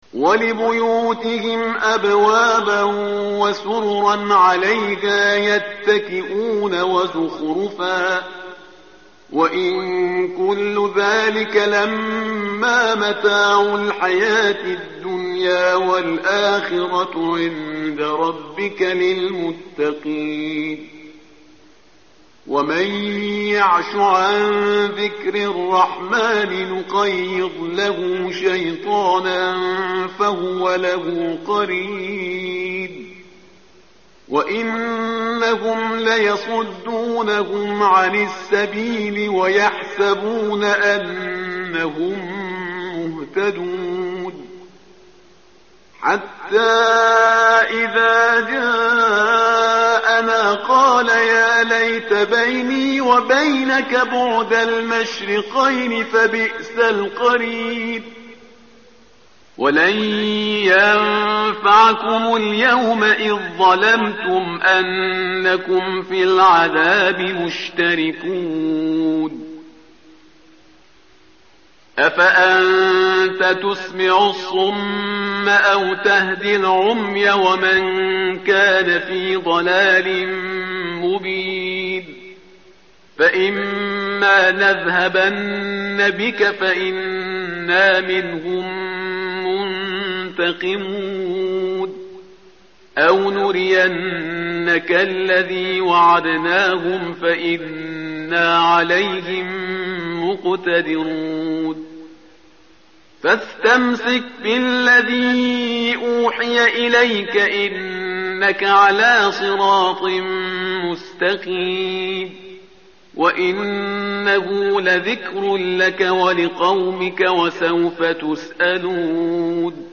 متن قرآن همراه باتلاوت قرآن و ترجمه
tartil_parhizgar_page_492.mp3